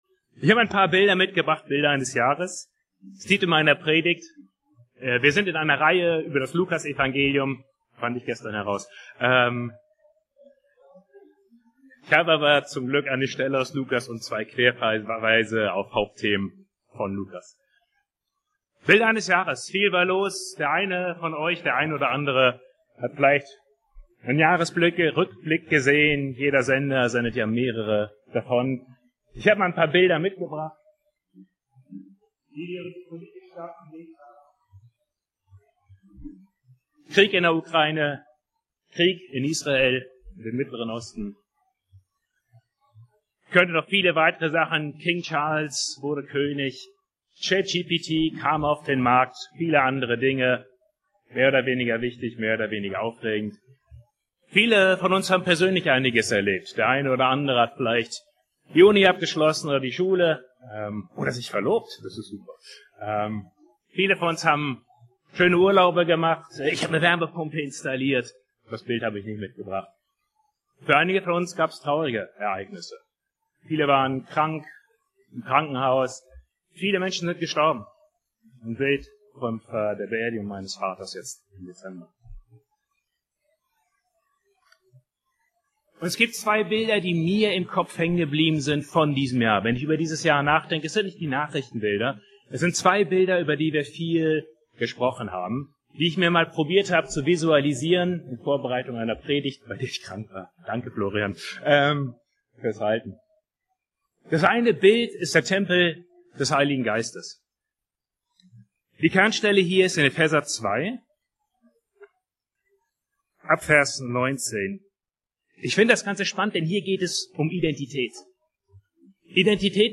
E-Mail Details Predigtserie